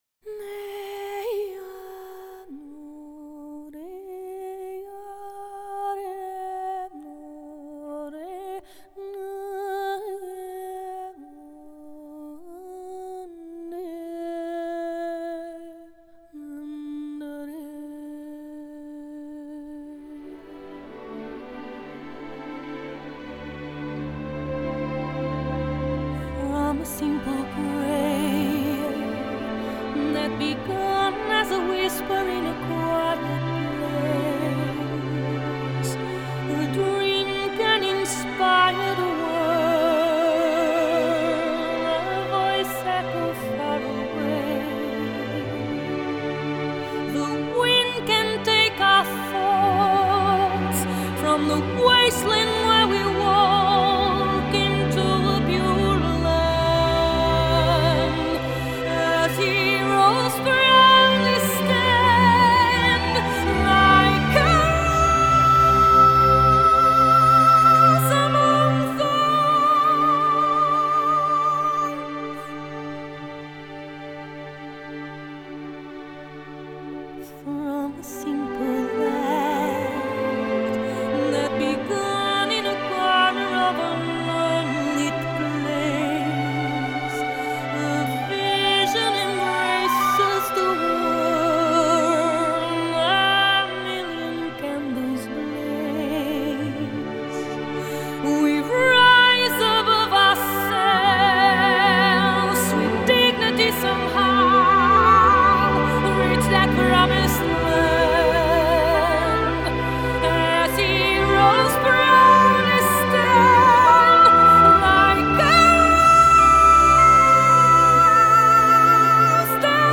Genre: Score
DSD-layer: Stereo, 5.1 Multichannel.